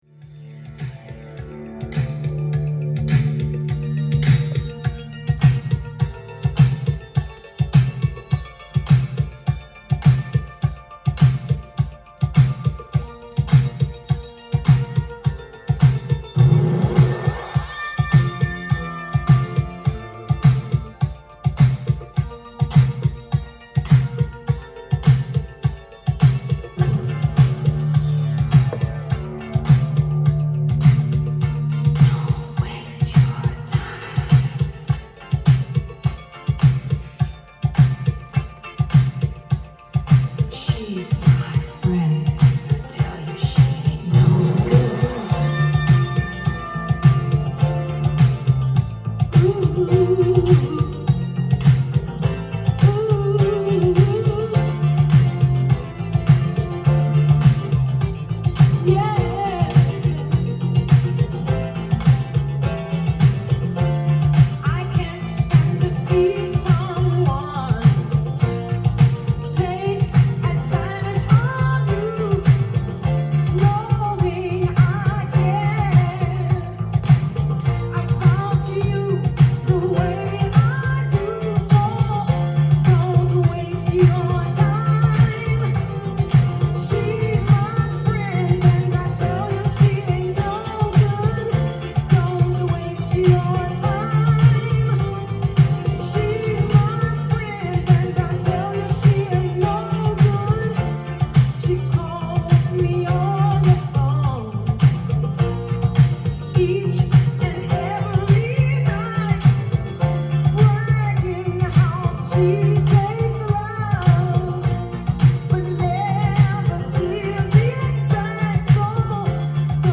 店頭で録音した音源の為、多少の外部音や音質の悪さはございますが、サンプルとしてご視聴ください。
男女ディスコ・デュオ
音が稀にチリ・プツ出る程度